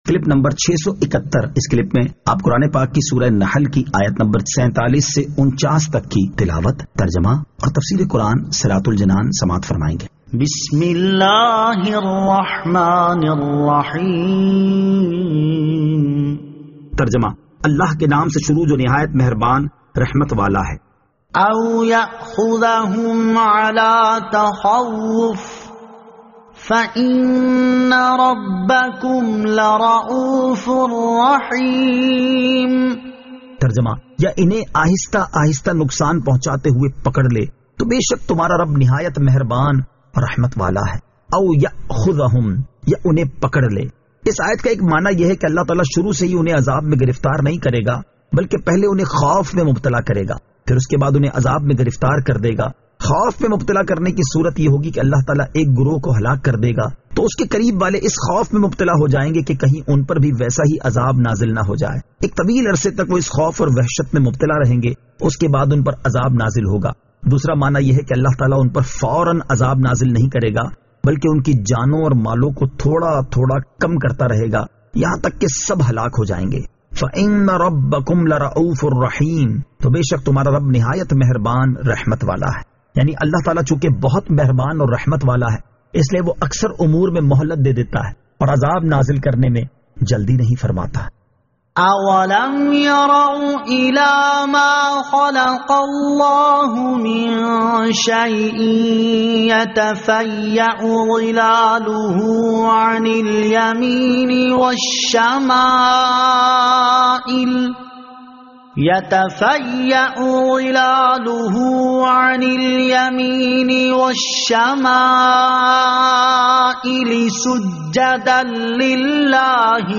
Surah An-Nahl Ayat 47 To 49 Tilawat , Tarjama , Tafseer